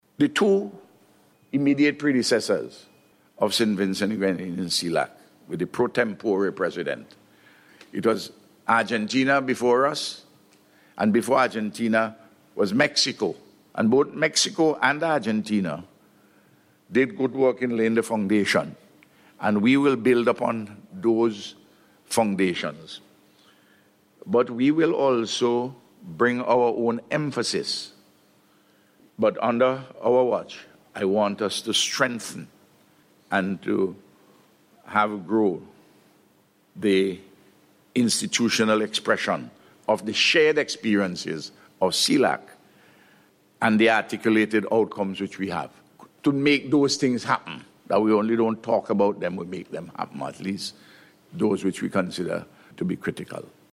So says Prime Minister Dr. Ralph Gonsalves, as he spoke at a ceremony at the Argyle International Airport, upon his return from the CELAC Summit in Argentina.